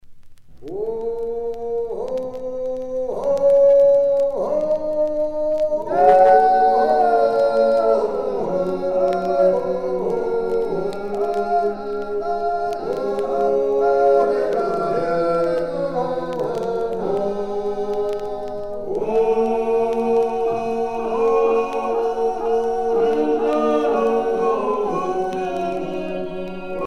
Chants de travail
Pièce musicale éditée